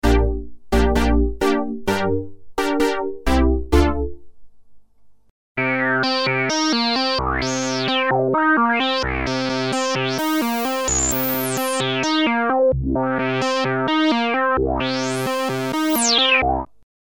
Brass & techno, 80's brass chords then techno sounds tweaking the filter env and resonance.
Unease_Juno-106_brasstech.mp3